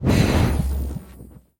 Minecraft Version Minecraft Version snapshot Latest Release | Latest Snapshot snapshot / assets / minecraft / sounds / mob / evocation_illager / cast2.ogg Compare With Compare With Latest Release | Latest Snapshot